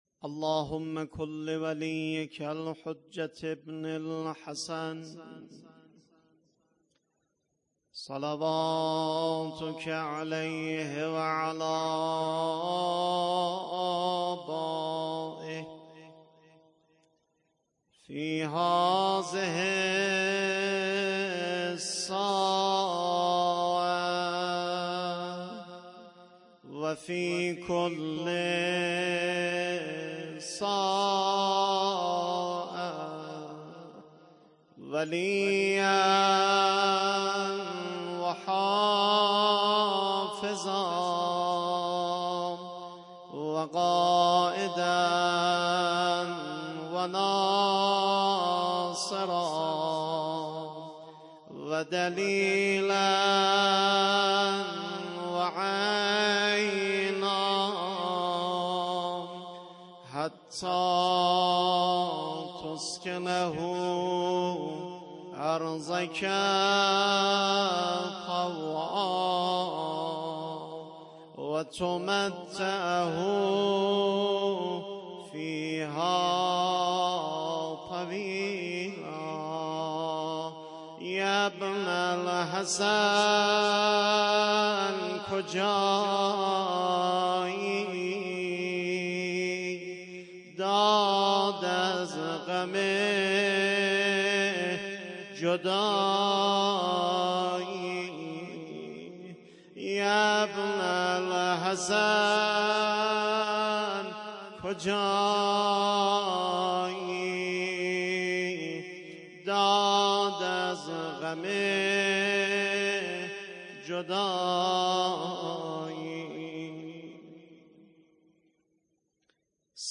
مناجات
شب تاسوعا محرم ۱۴۰۱